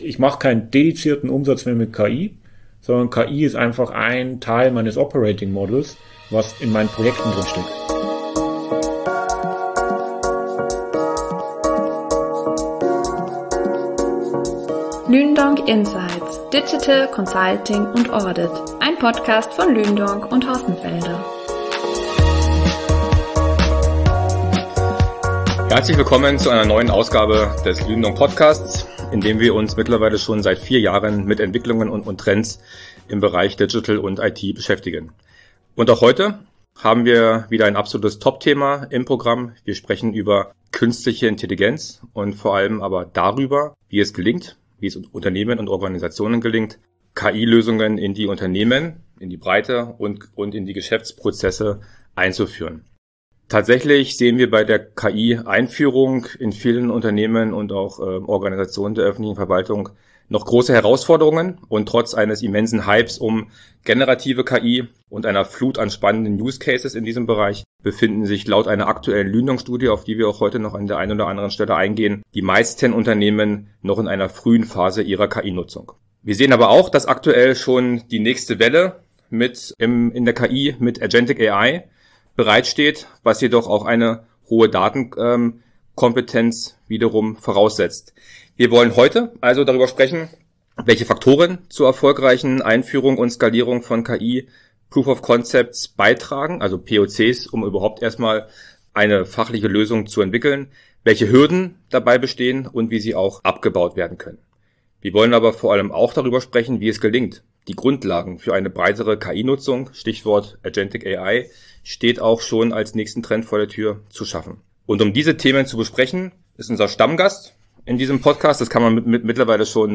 Ein praxisnahes, kritisches Gespräch über die Realität jenseits des Buzzword-Bingos – mit klarem Blick auf das, was kommt.